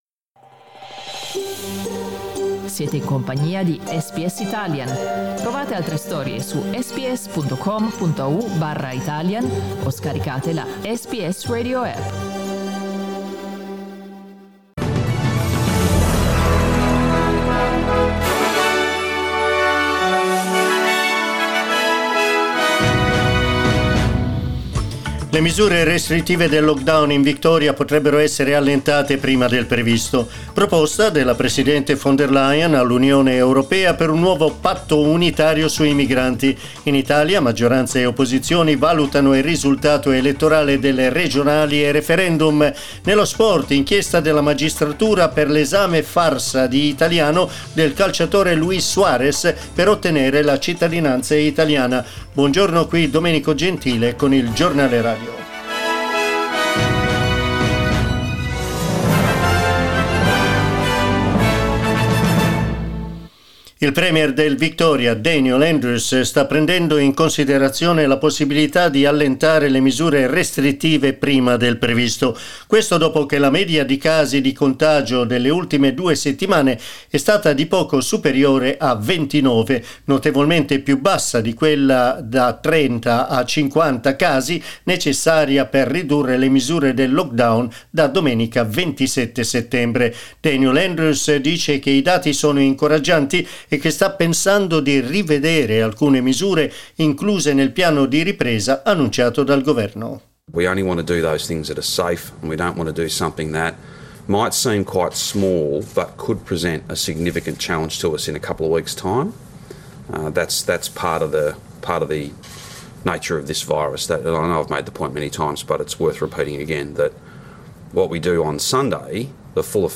Our news bulletin (in Italian) broadcast at 9am.